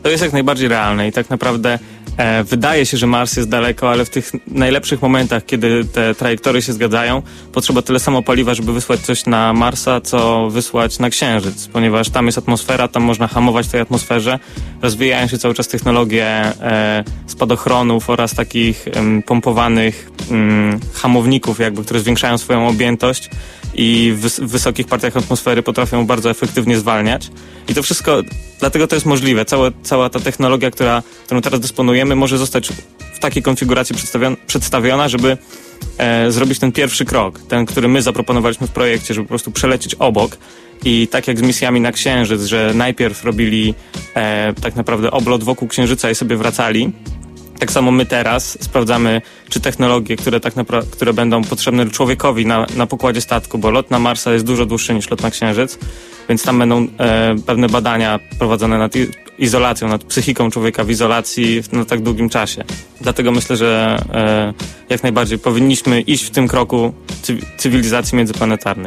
nagranie Akademickiego Radia Luz Politechniki Wrocławskiej: